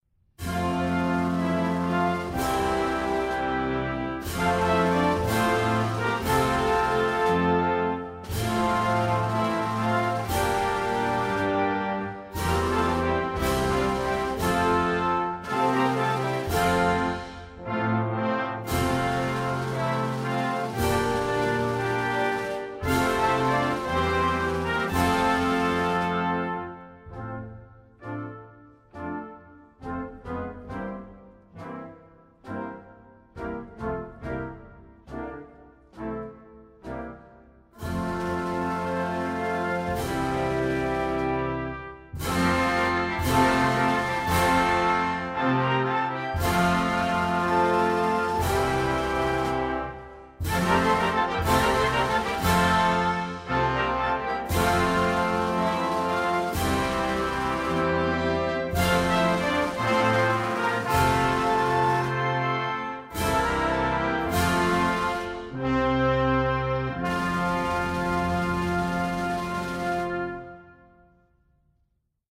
National_Anthem_of_Guinea_by_US_Navy_Band.mp3